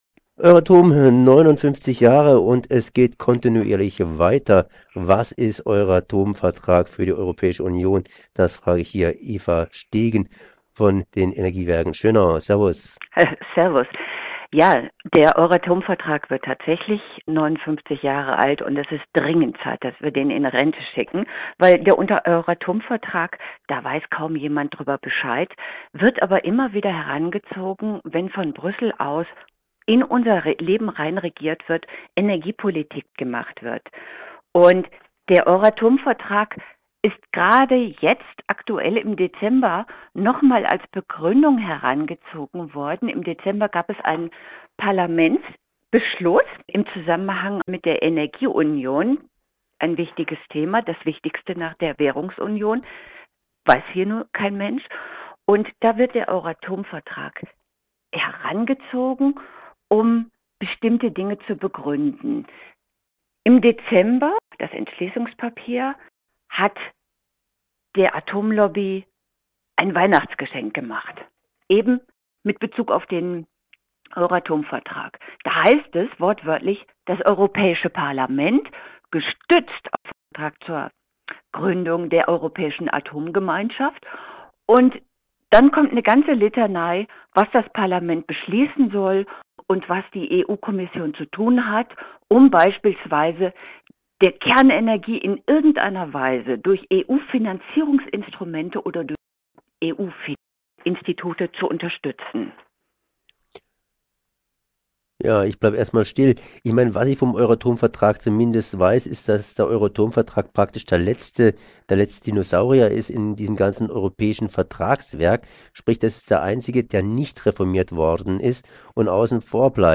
Eine Studiodebatte
aus dem Sendestudio des Europäischen Parlaments in Straßburg